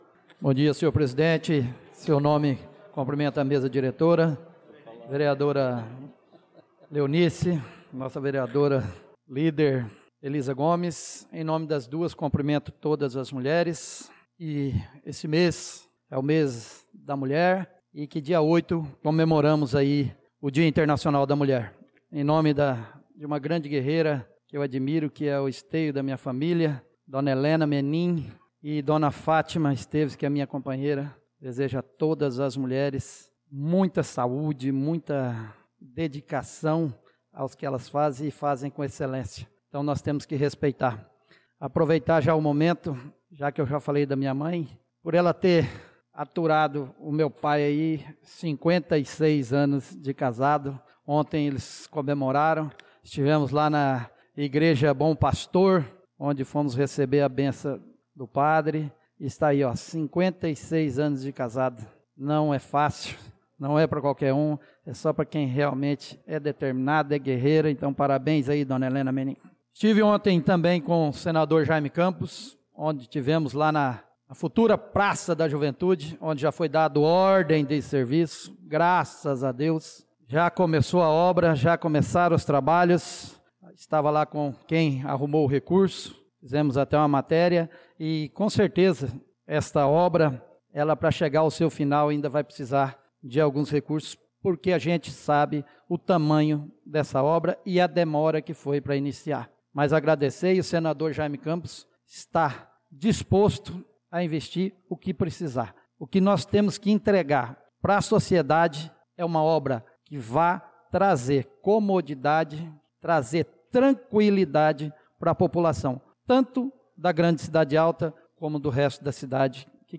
Pronunciamento do vereador Marcos Menin na Sessão Ordinária do dia 06/03/2025